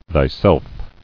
[thy·self]